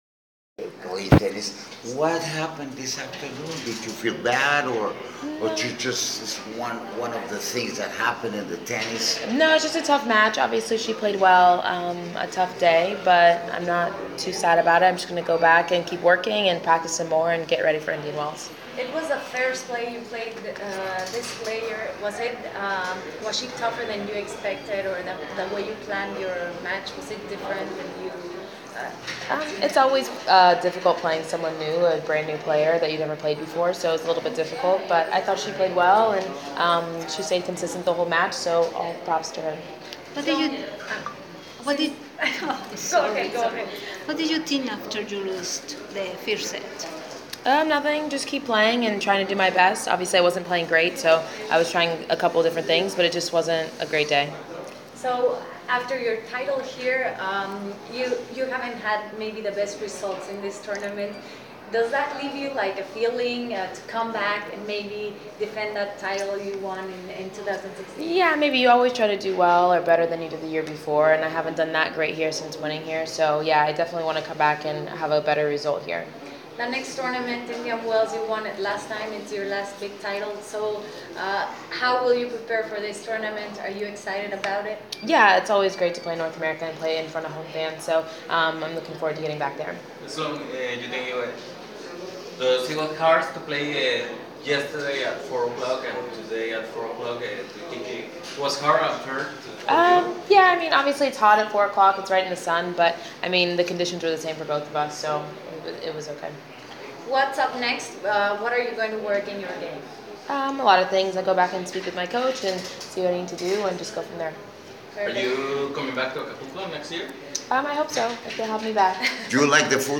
Press Conference – Sloane Stephens (27/02/2019)